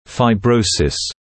[fʌɪ’brəʊsɪs][фай’броусис]фиброз